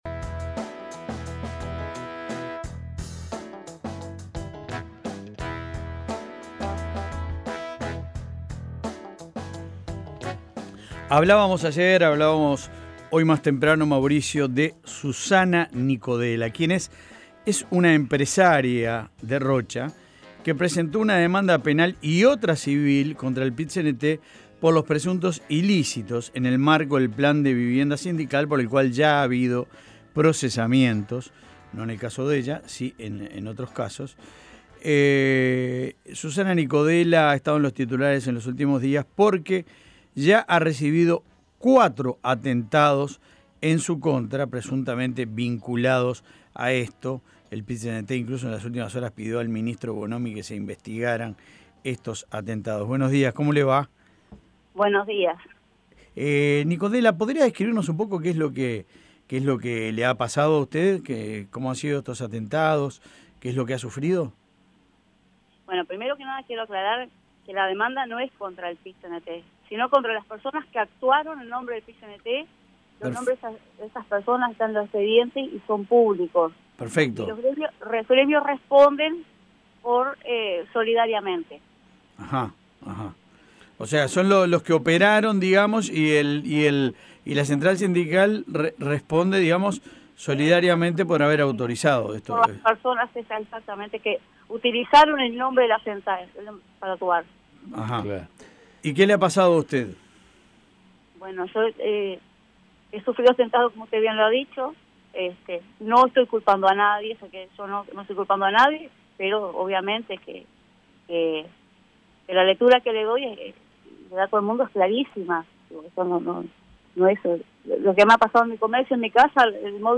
En diálogo con La Mañana de El Espectador la empresaria dijo que "no culpa a nadie" pero aclara que "la lectura es clarísima".